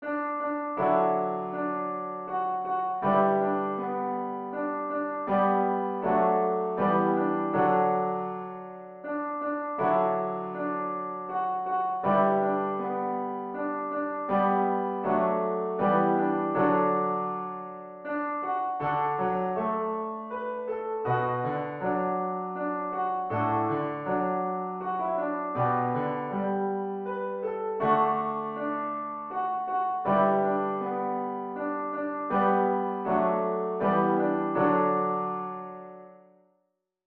Lower key
Psalm-91-Audio-Lower.wav